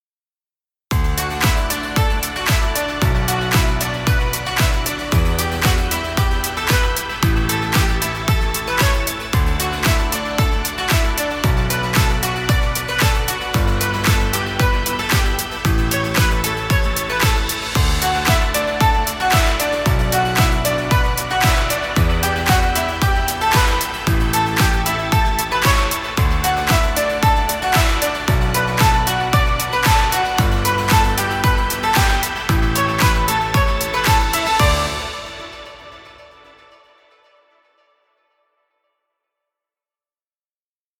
Happy motivational music. Background music Royalty Free.